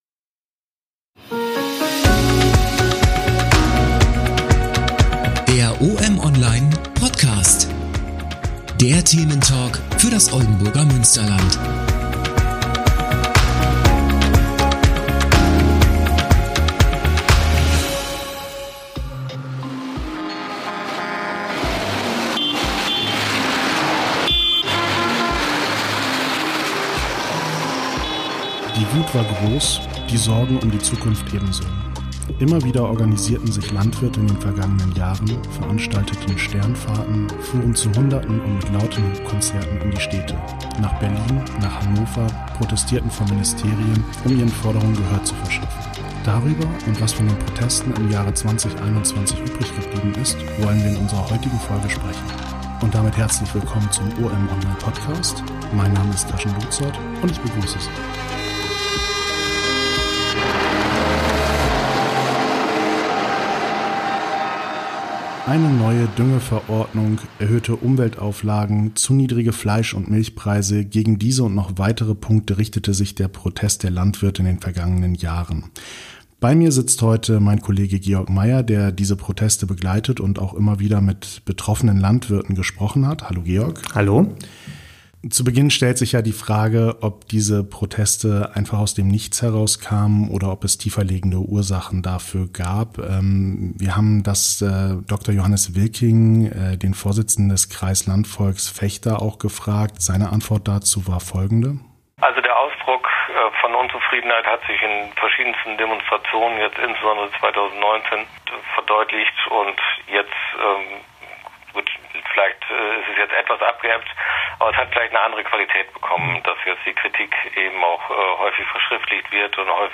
Der Thementalk im Oldenburger Münsterland.